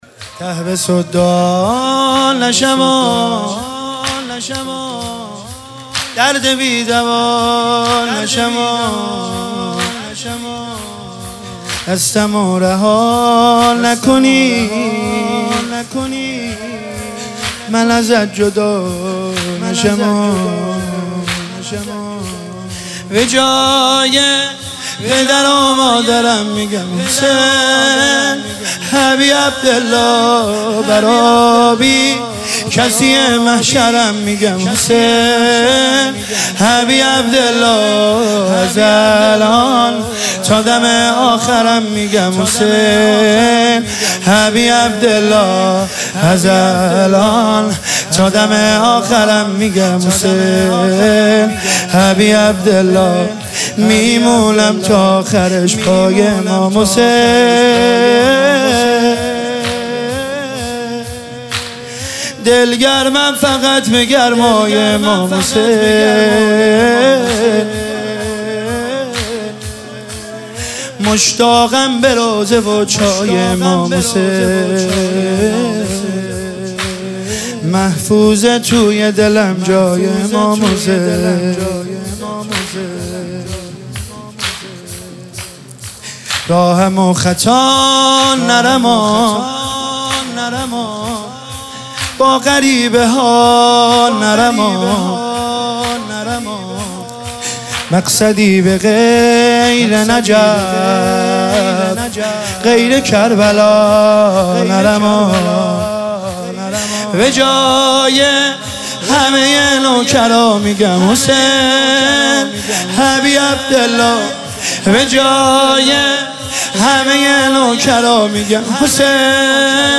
مداحی شور شب چهارم محرم